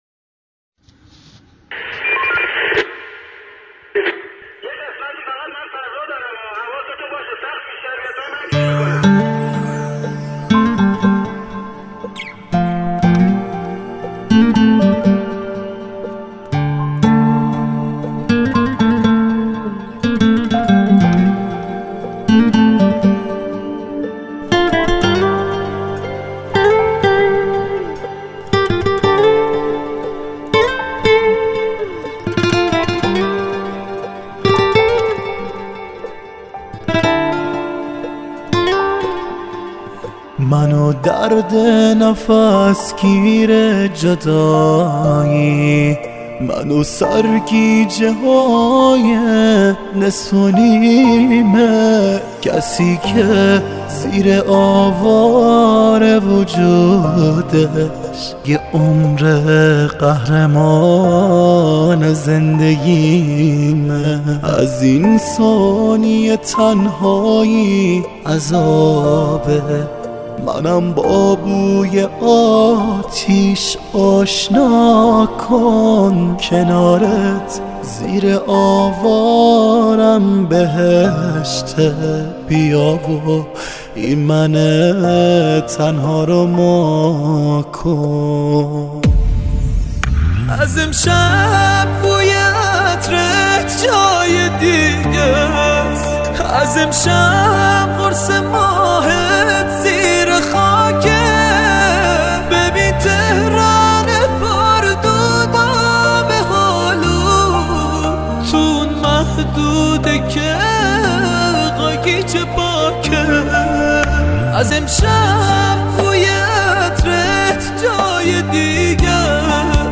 گیتار کلاسیک